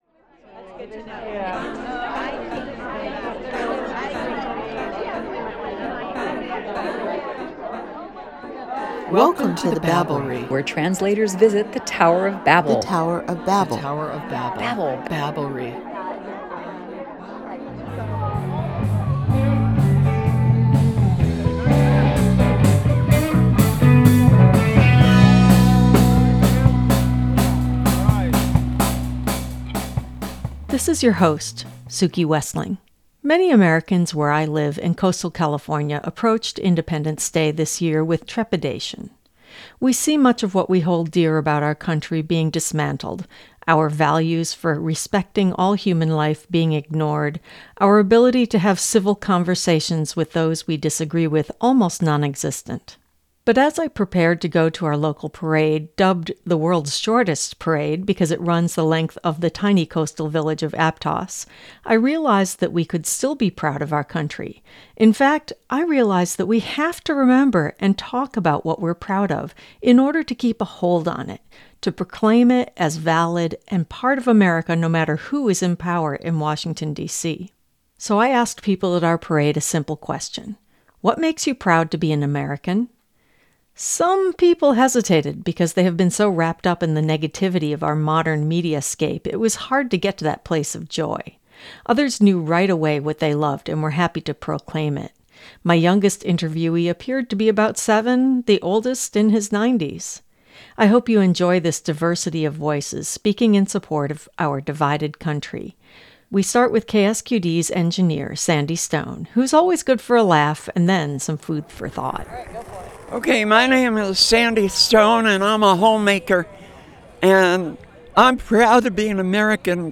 Why are you proud to be American? In these interviews at an Independence Day parade on Coastal California, residents talk about reaching for their pride at a time when our political landscape is so fraught.